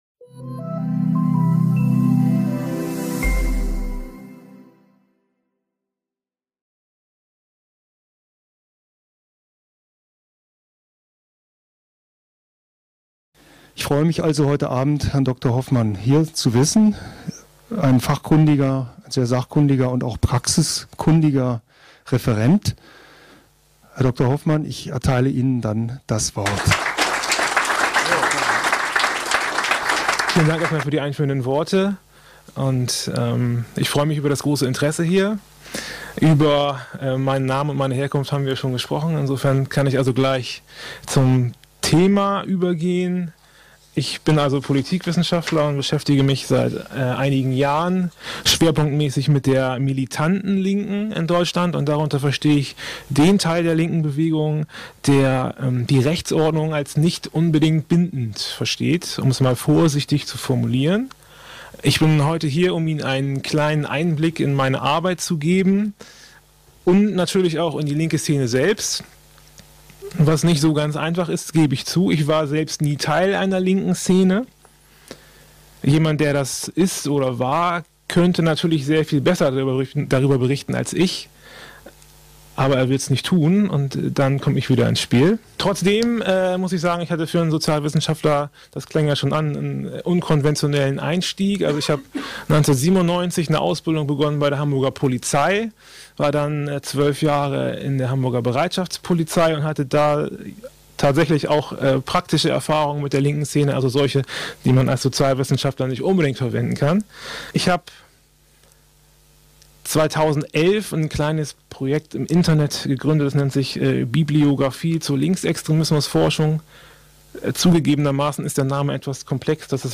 Zentrum „Rote Flora“ in Hamburg – in seinem Vortrag Ideologien,